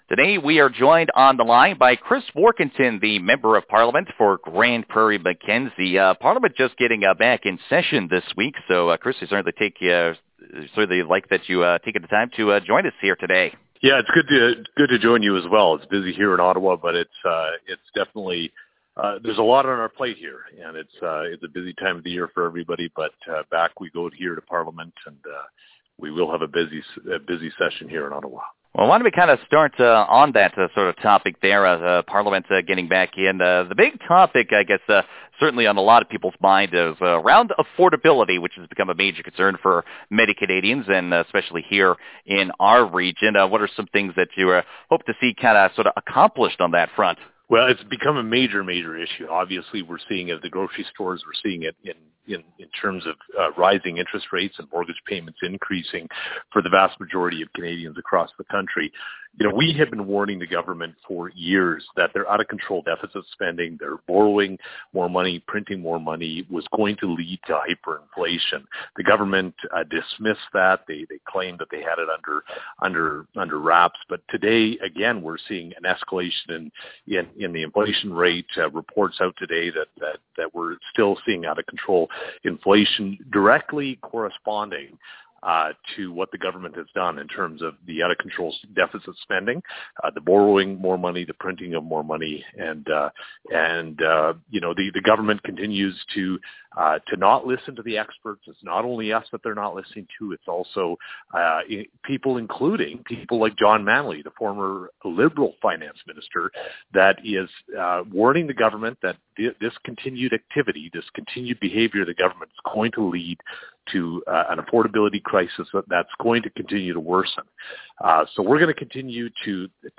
The full interview we did with Warkentin can be found below.
Warkentin-Interview-September-19.wav